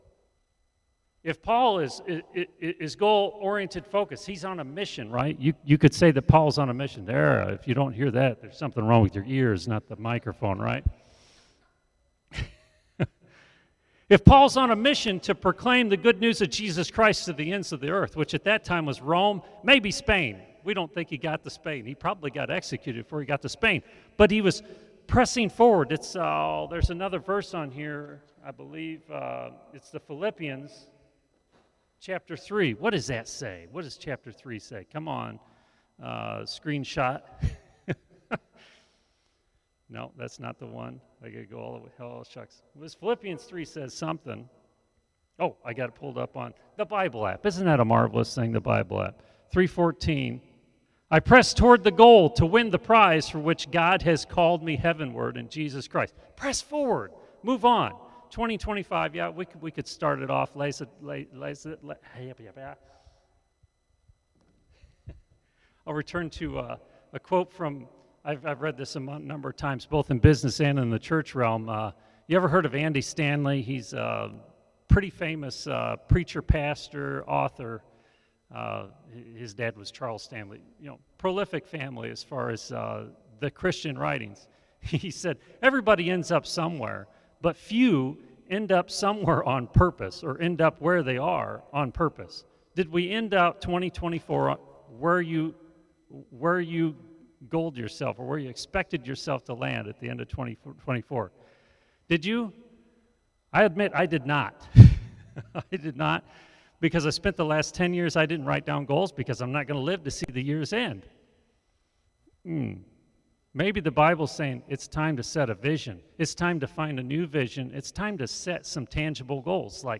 … continue reading 253 つのエピソード # Religion # Sunday Service # East Bend Mennonite Church # Christianity